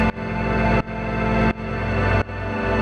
Index of /musicradar/sidechained-samples/170bpm
GnS_Pad-dbx1:2_170-A.wav